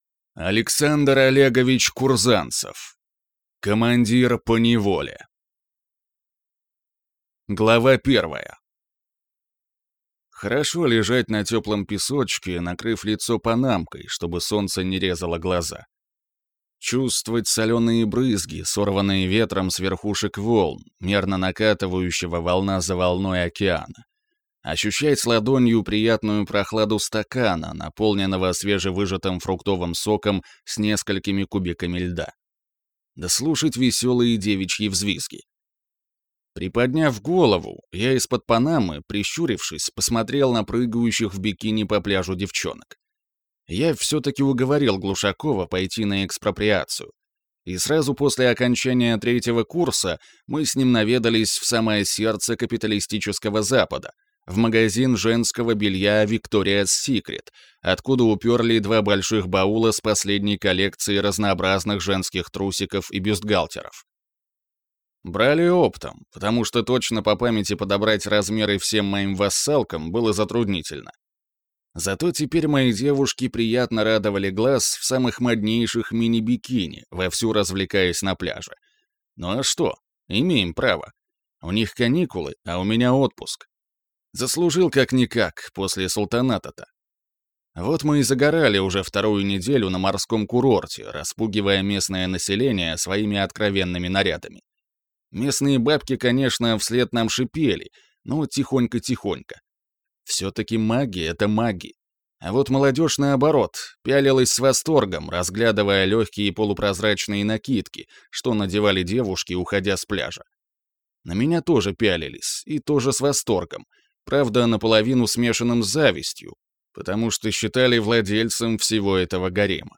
Аудиокнига Командир поневоле | Библиотека аудиокниг